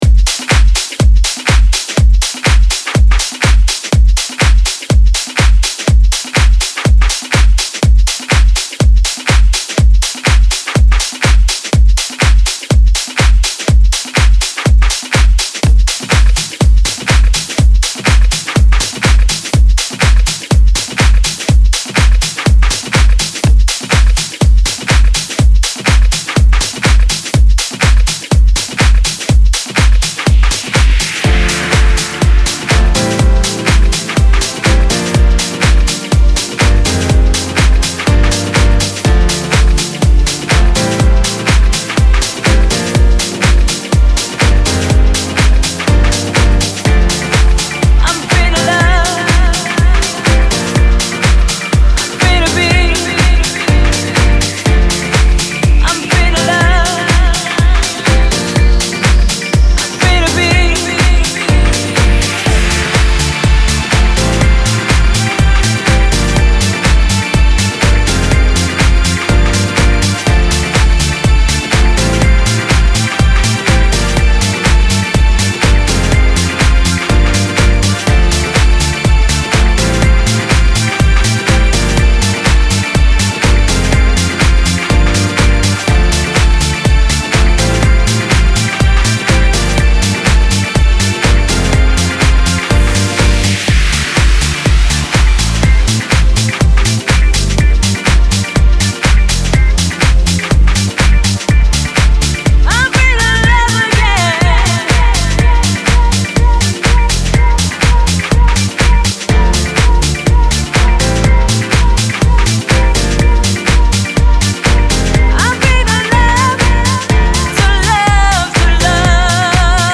House Dub Mix